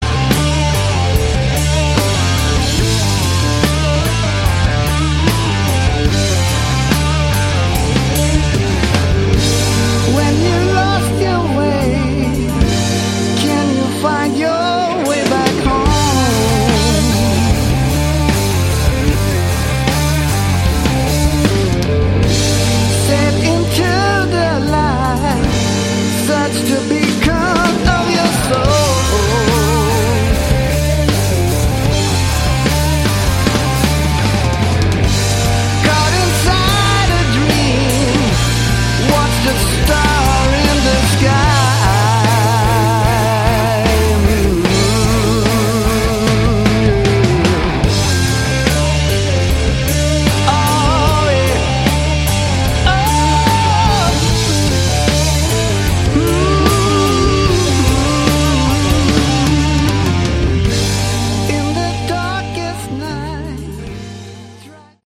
Category: Hard Rock
guitar, vocals
bass
drums and cowbell